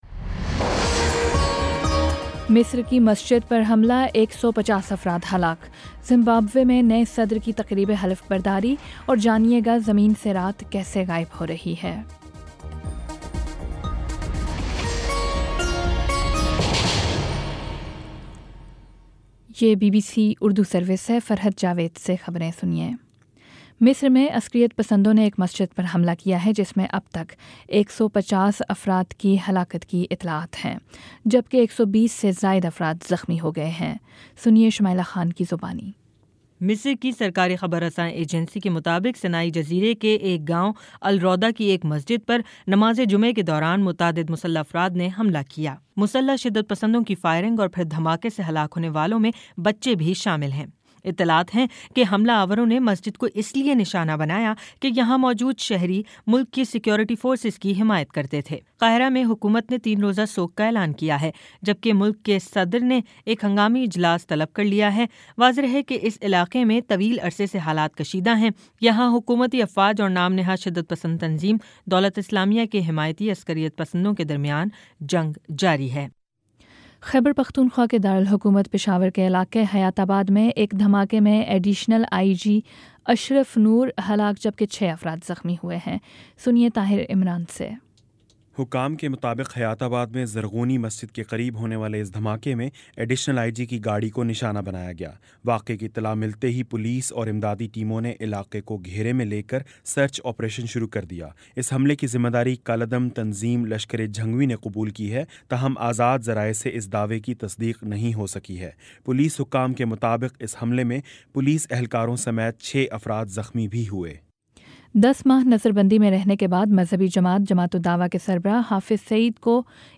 نومبر24 : شام سات بجے کا نیوز بُلیٹن